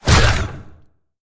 File:Sfx creature squidshark flinch 02.ogg - Subnautica Wiki
Sfx_creature_squidshark_flinch_02.ogg